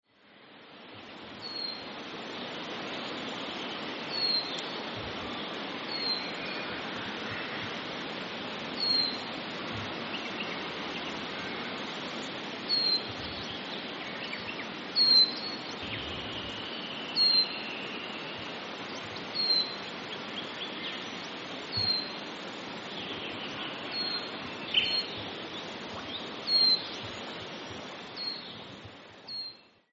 Silvereye - Zosterops lateralis
Voice: mournful 'tsee'.
Call 1: single bird calling.
Silvereye.mp3